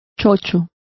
Complete with pronunciation of the translation of senile.